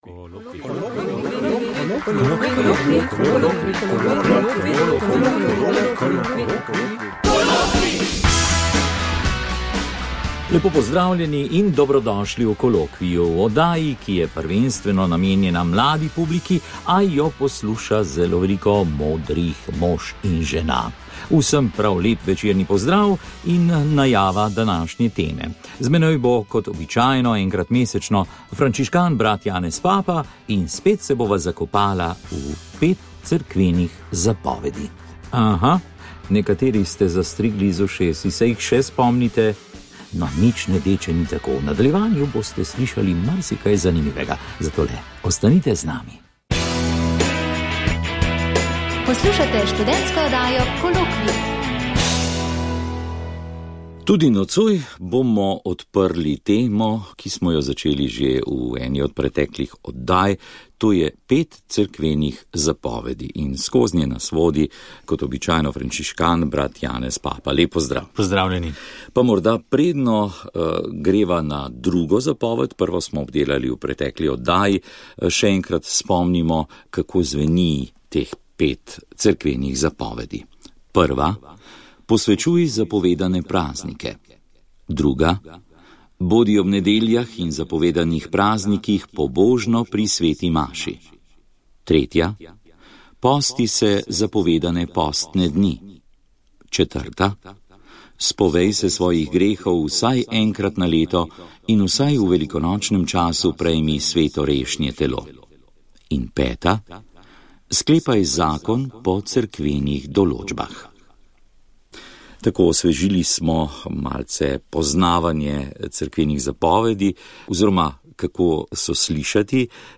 Pogovor o CERKVENIH ZAPOVEDIH
V drugi polovici leta 2019 bo v oddaji Kolokvij na radiu Ognjišče, predvidoma vsak mesec na 3. petek v mesecu ob 21. uri, potekal pogovor in razmišljanje o peterih cerkvenih zapovedih.